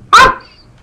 dogs_0009.wav